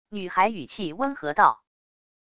女孩语气温和道wav音频